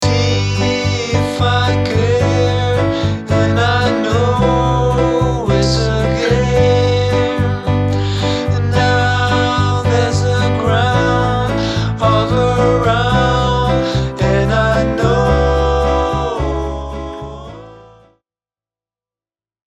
After keeping the generated melodies that i liked the most, generating others (Flow Machines took into account what I had kept), I got a verse (played here by a Logic Pro electric piano) :
You’ll notice that the rising melody at 0:18 is very typical: it is accompanied by a harmonic descent at 0:22.